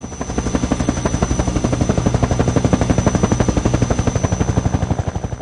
Kategorien: Soundeffekte